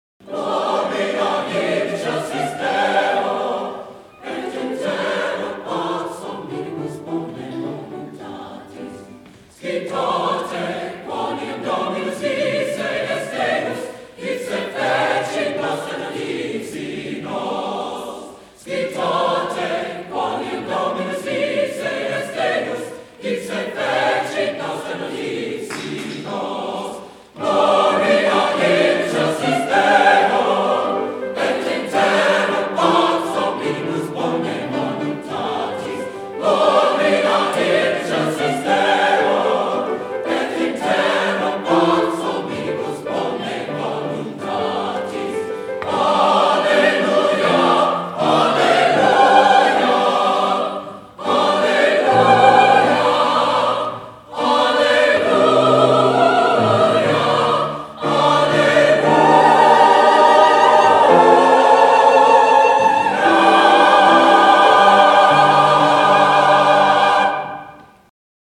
Highland Park, MI, High School Concert Choirs, 1954-1969
Highland Park High School, Michigan